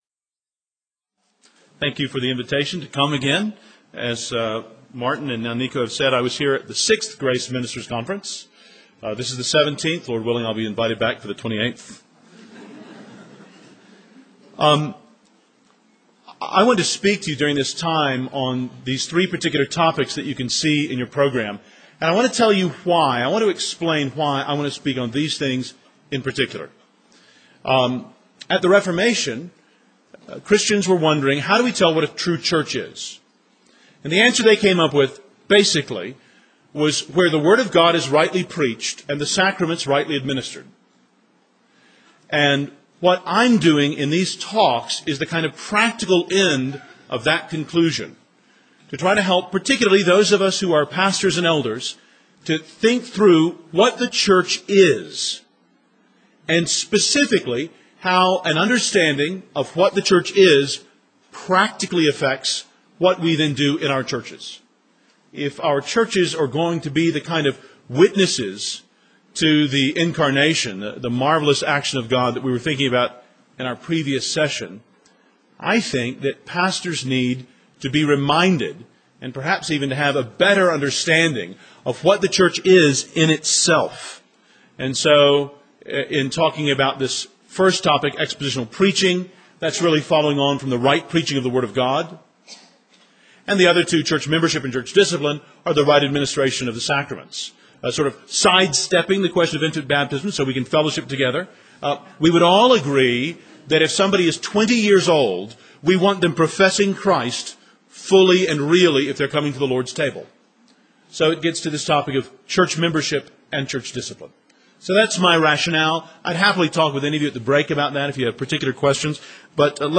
08 Q & A Session